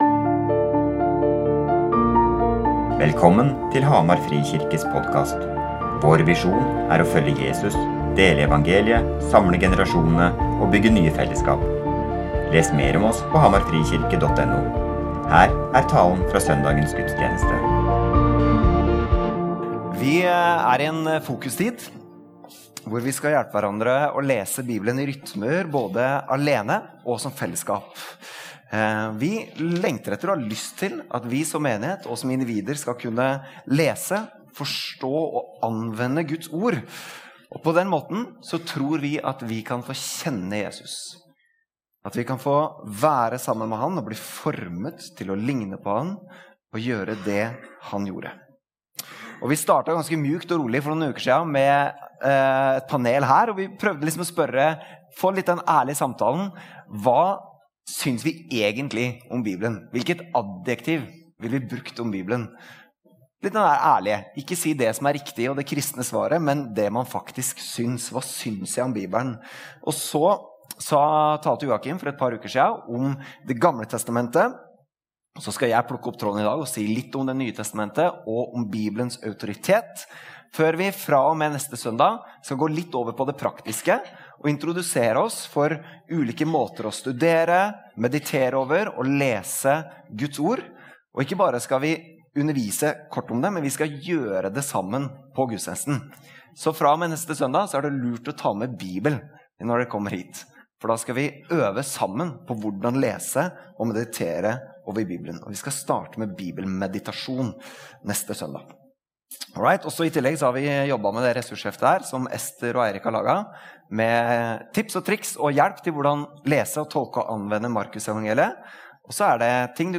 Gudstjenesten